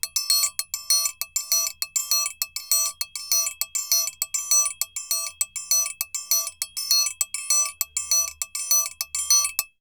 Triangle_Samba 100_1.wav